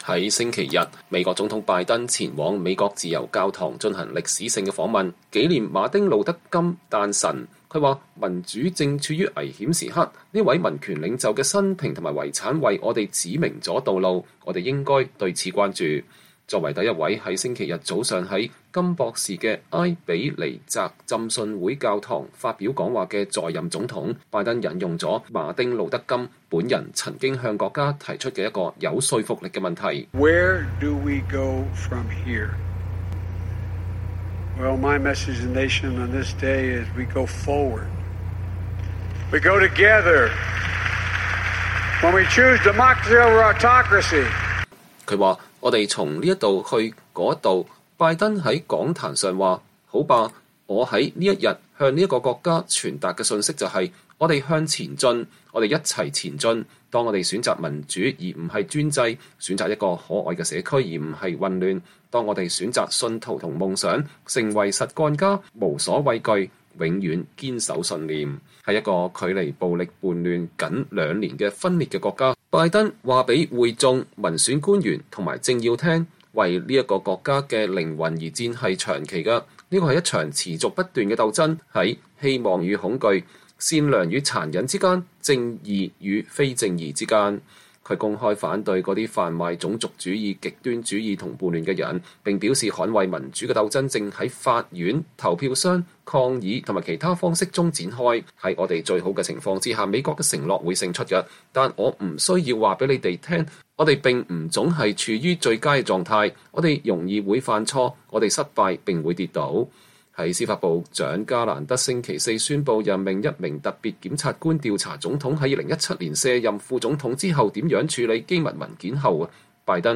作為第一位在週日早上在金博士的埃比尼澤浸信會教堂發表講話的在任總統，拜登引用了金本人曾經向國家提出的一個有說服力的問題。